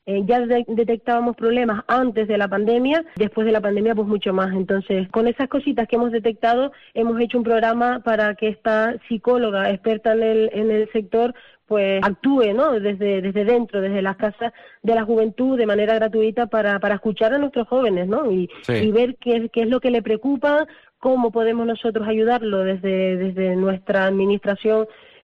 Priscila Díaz, concejala de Juventud de San Juan de la Rambla, sobre el servicio de psicólogo para jóvenes